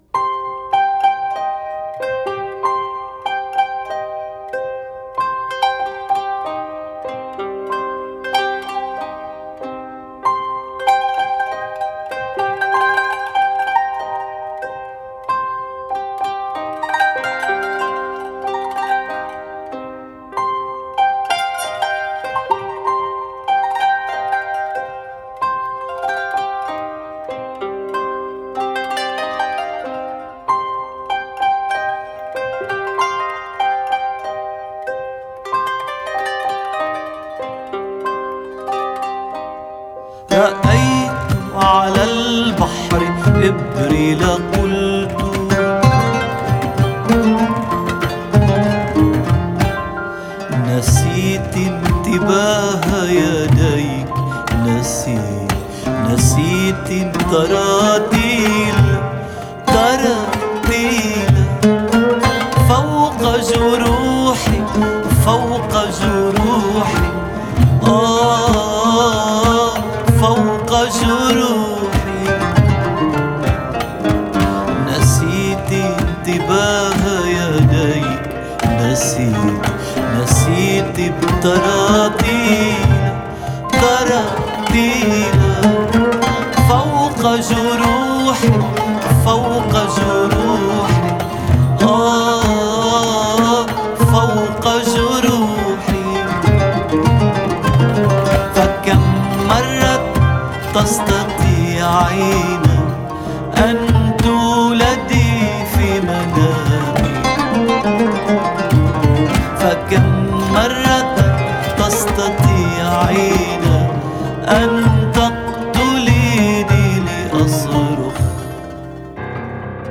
ترانه عربی اغنية عربية